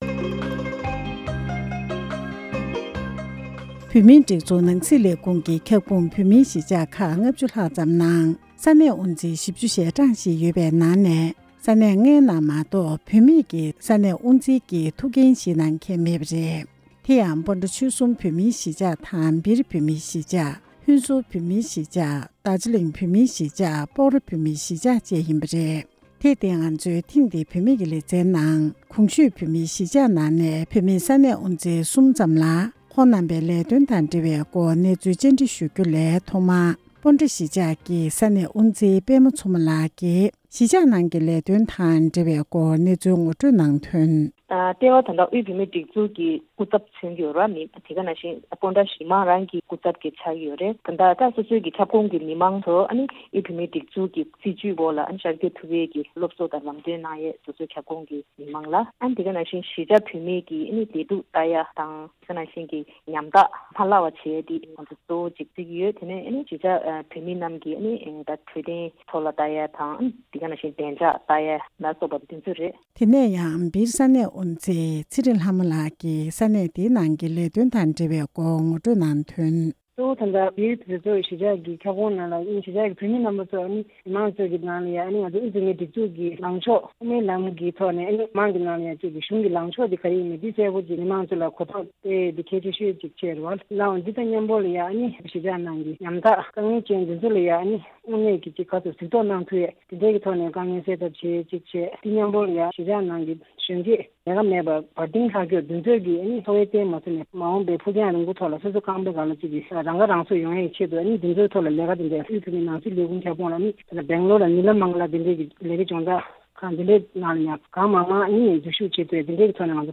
གནས་འདྲི་ཞུས་སྟེ་གནས་ཚུལ་ཕྱོགས་སྒྲིག་ཞུས་པ་ཞིག་གསན་རོགས་གནང་།།